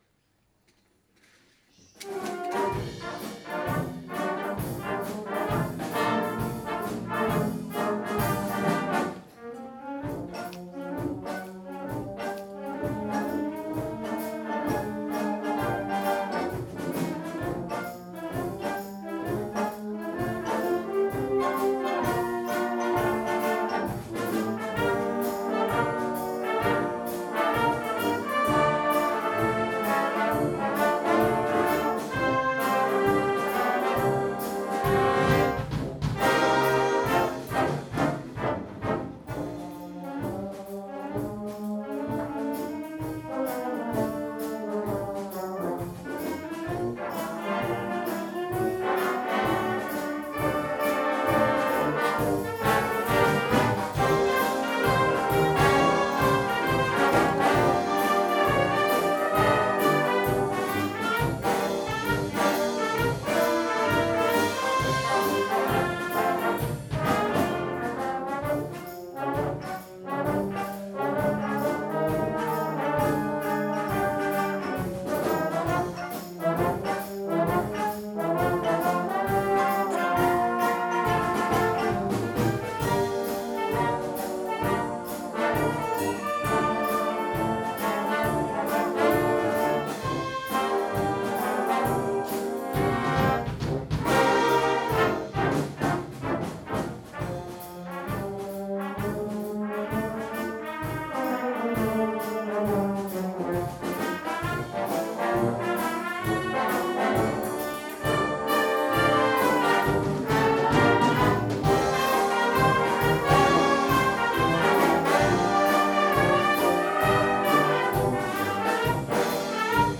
Harmonie
Plus de 60 musiciens